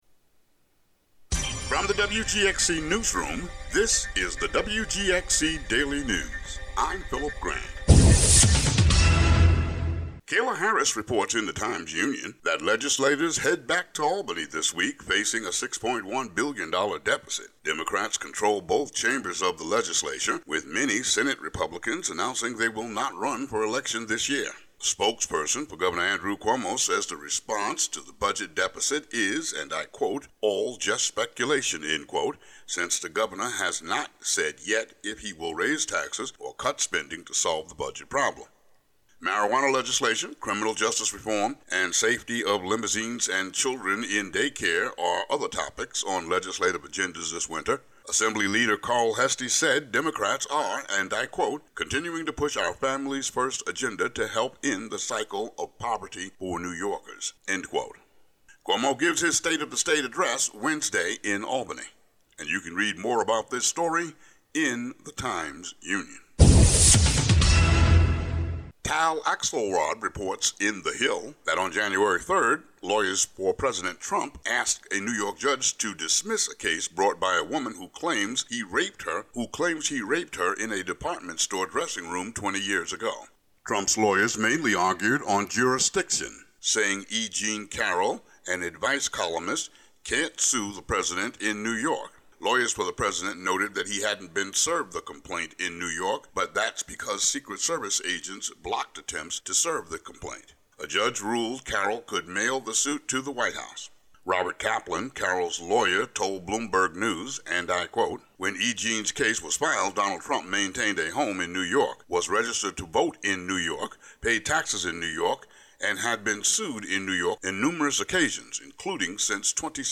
The audio version of the news update for Mon., Jan. 6.